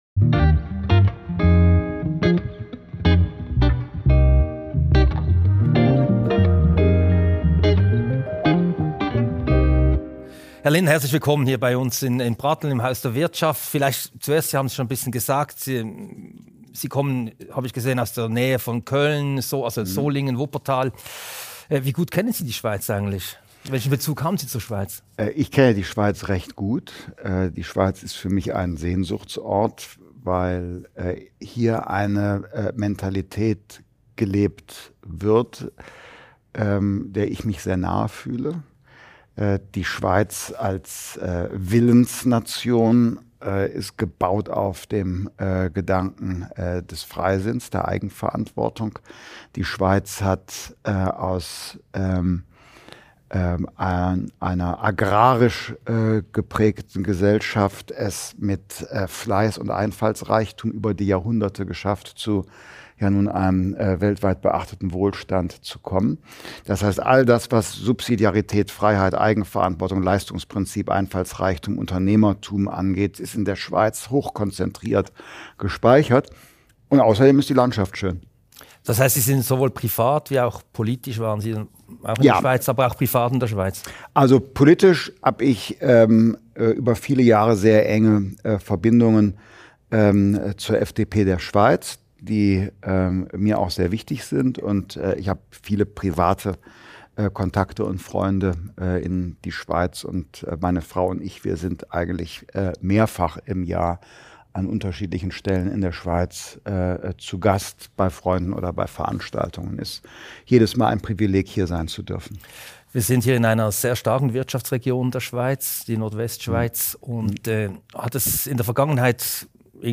Wir haben das Gespräch aufgezeichnet.